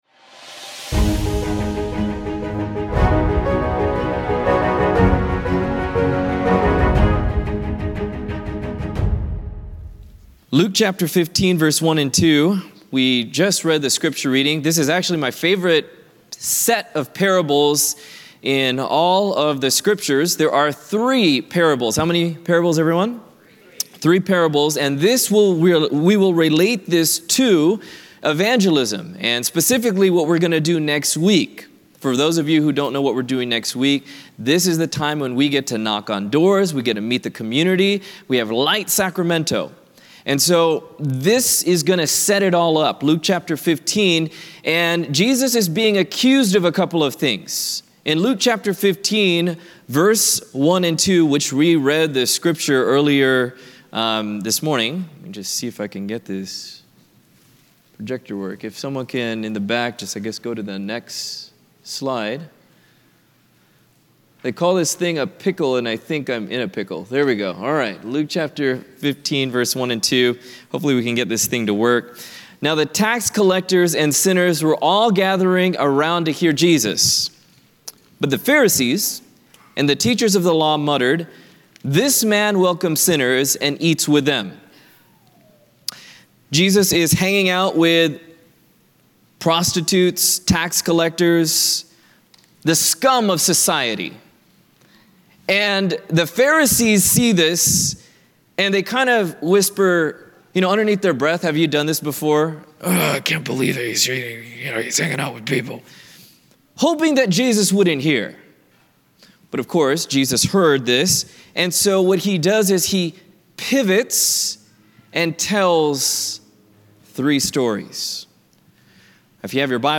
A message from the series "Central Sermons."